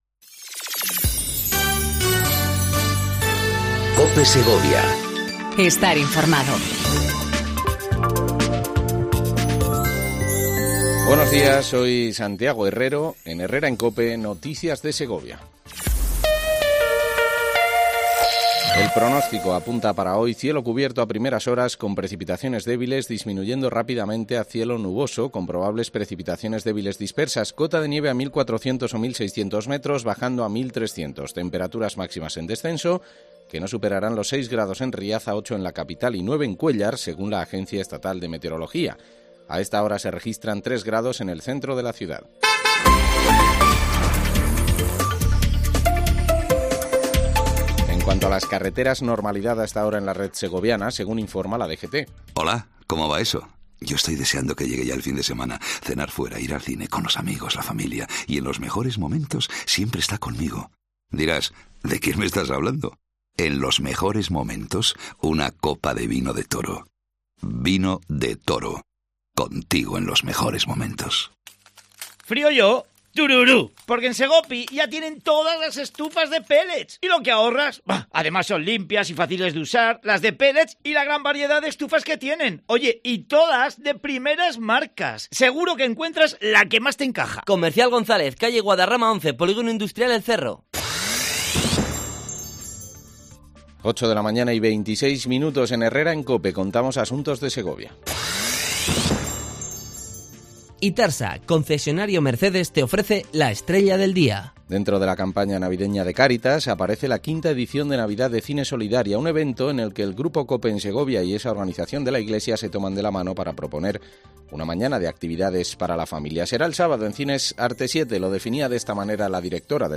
AUDIO: Segundo informativo local en cope segovia